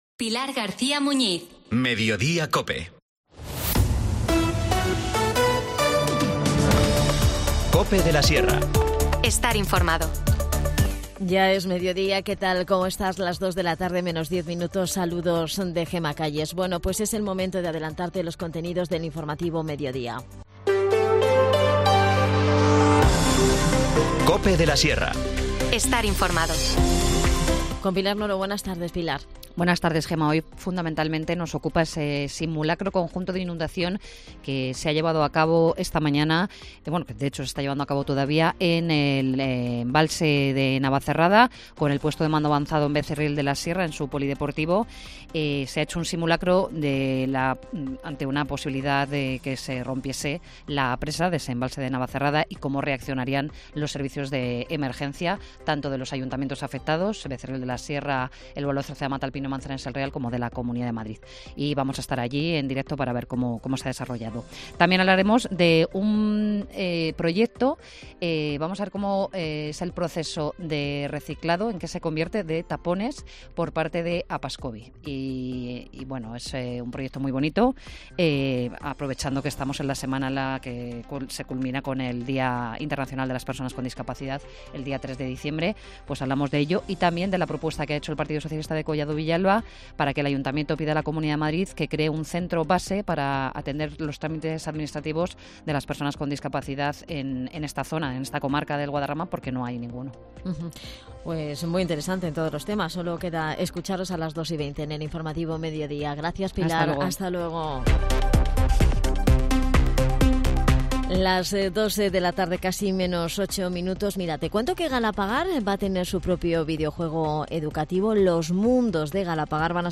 Nos cuenta todos los detalles Beatriz Gutiérrez, concejal de Educación.